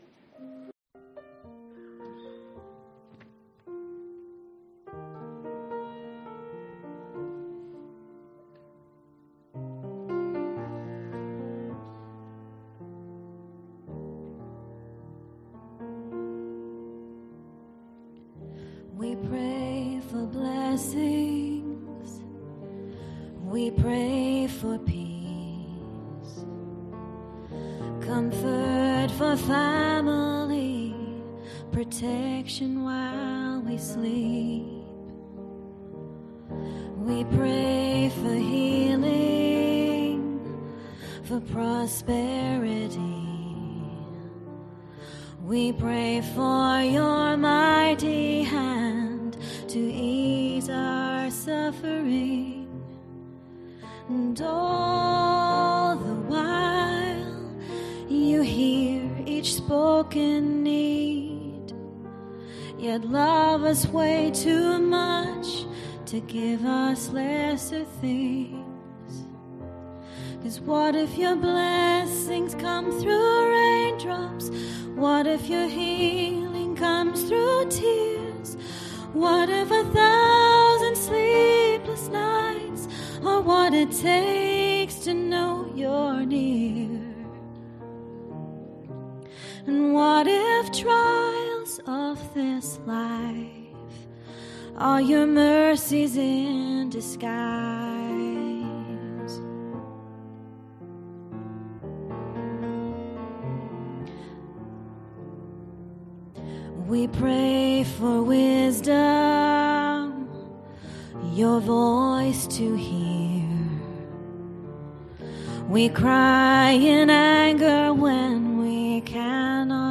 Ministry Song https
Service Type: pm